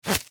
Minecraft Version Minecraft Version snapshot Latest Release | Latest Snapshot snapshot / assets / minecraft / sounds / entity / leashknot / place1.ogg Compare With Compare With Latest Release | Latest Snapshot